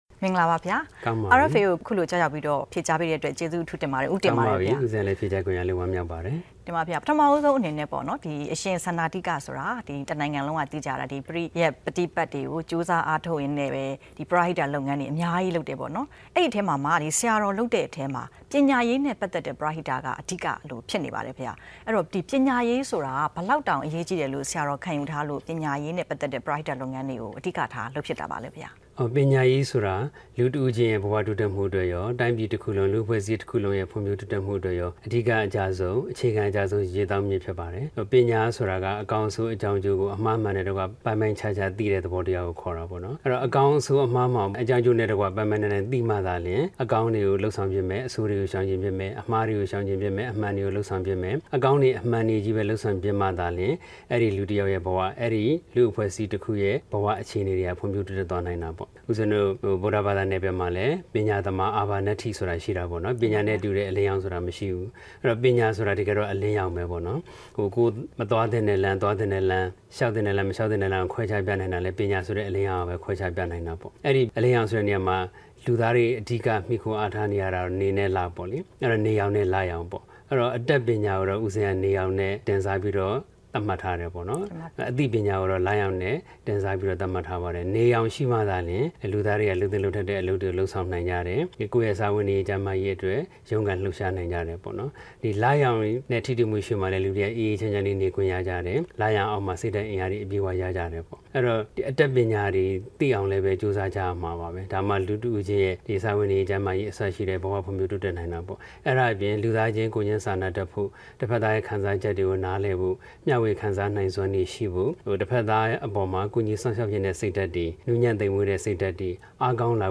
ပညာရေးပရဟိတအကြောင်း မေးမြန်းချက်